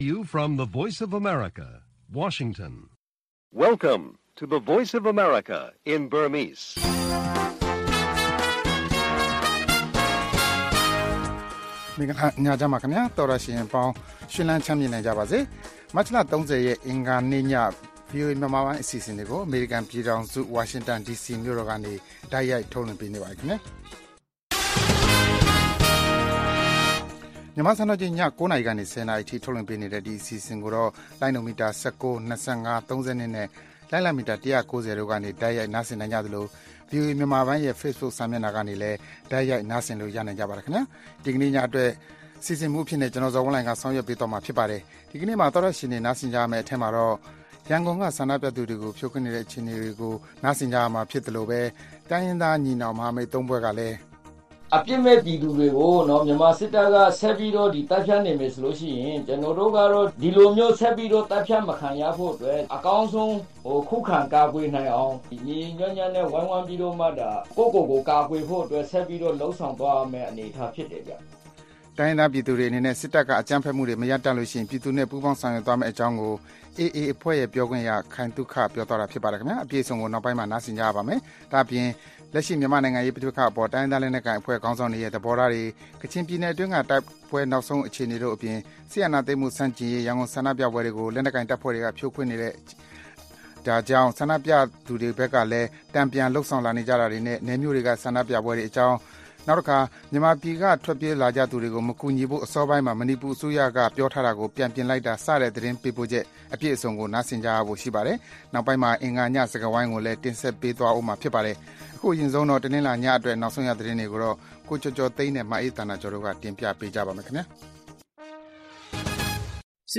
သီတင်းပတ်စဉ် - အင်္ဂါနေ့ညစကားဝိုင်းအစီအစဉ်။